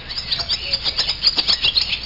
Swallows Sound Effect
swallows.mp3